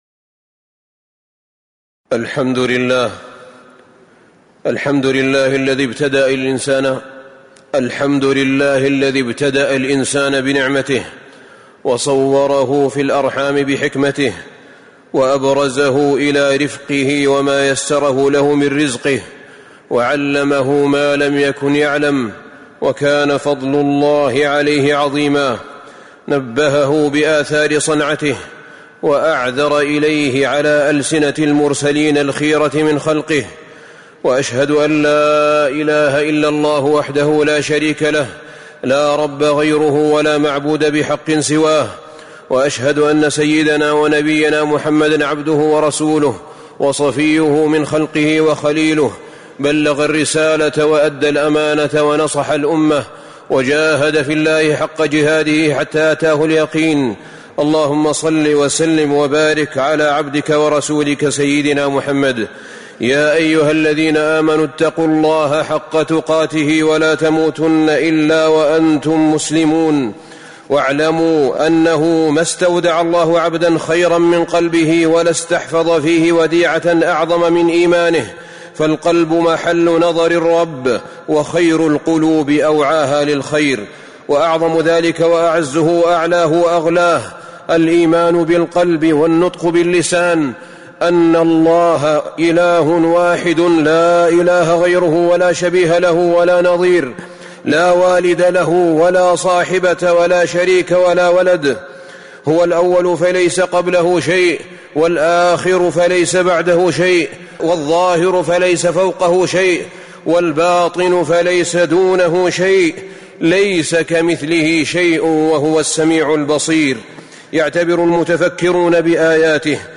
تاريخ النشر ٢٦ ذو الحجة ١٤٤٤ هـ المكان: المسجد النبوي الشيخ: فضيلة الشيخ أحمد بن طالب بن حميد فضيلة الشيخ أحمد بن طالب بن حميد ليس كمثله شئ وهو السميع البصير